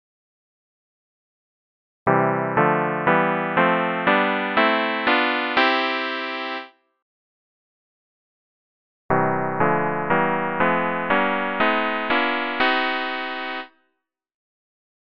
ParallelTriadsMajorScale.mp3